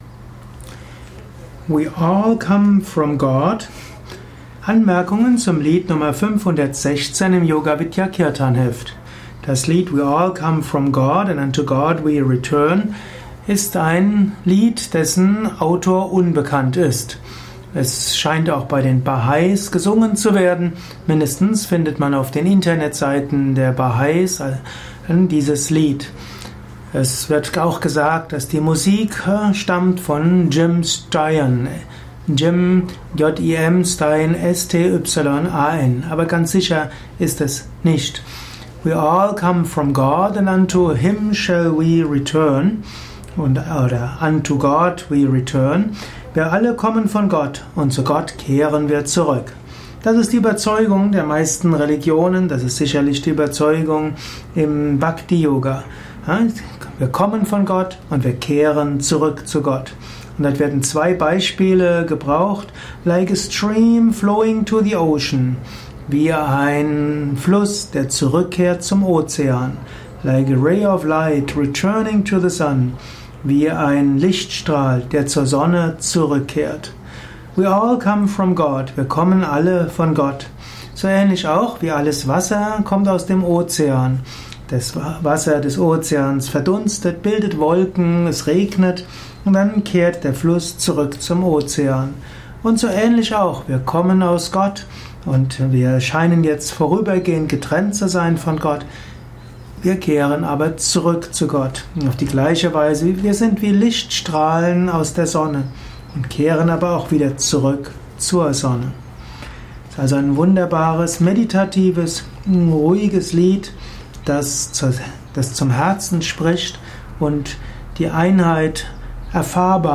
Audio mp3 Erläuterungen